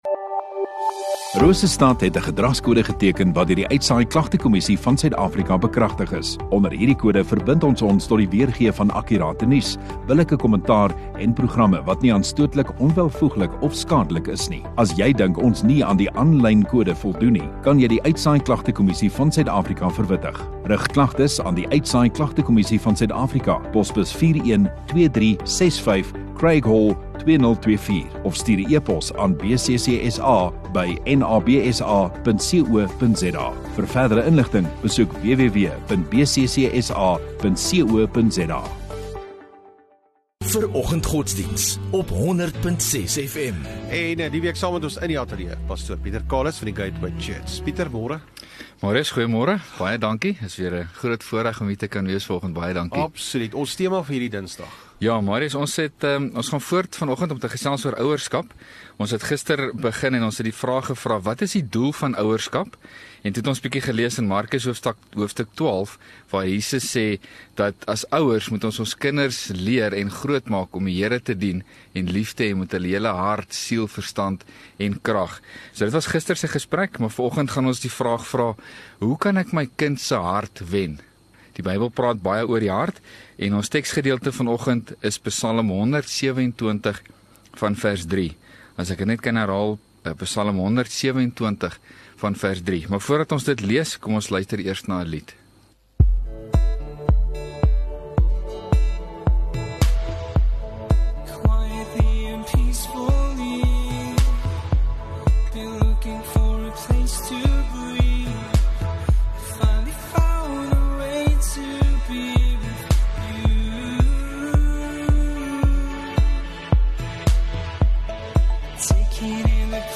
28 Jan Dinsdag Oggenddiens